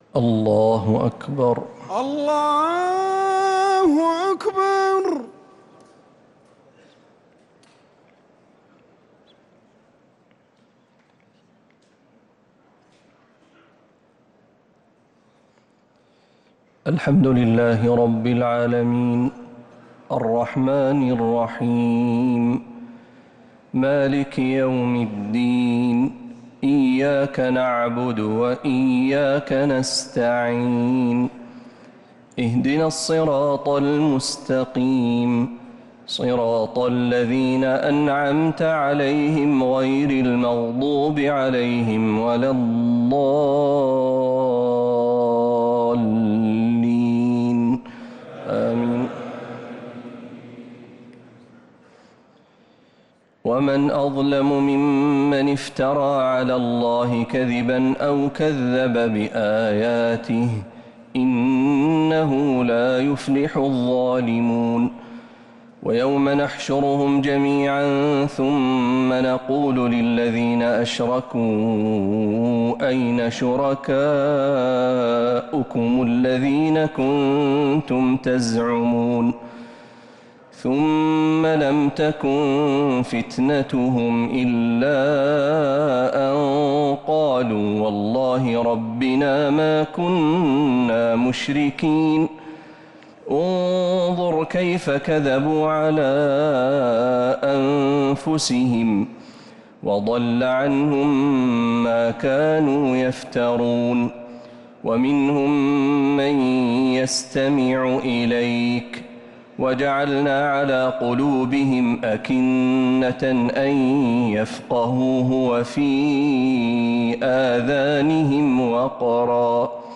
تراويح ليلة 9 رمضان 1447هـ من سورة الأنعام (21-73) | Taraweeh 9th niqht Ramadan 1447H Surat Al-Anaam > تراويح الحرم النبوي عام 1447 🕌 > التراويح - تلاوات الحرمين